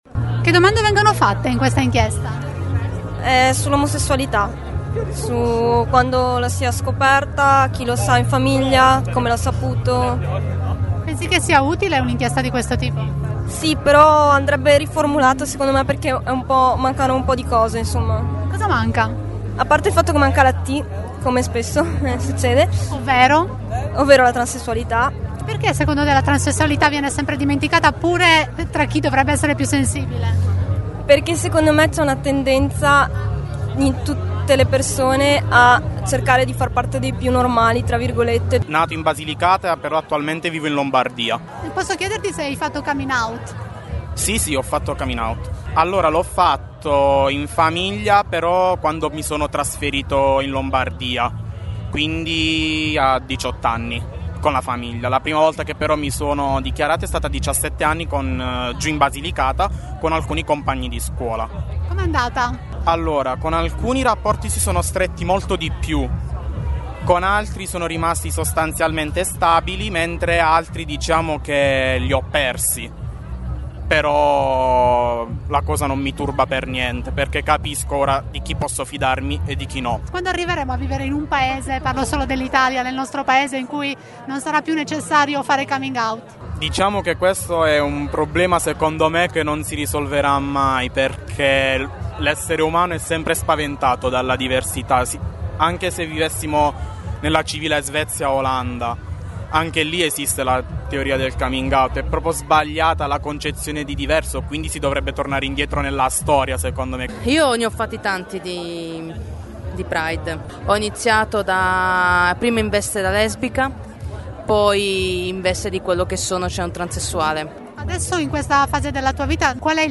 Ecco le voci di alcuni manifestanti, raccolte prima che il corteo uscisse dal parco del Cavaticcio
Voci dal Pride